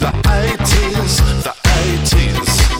electropop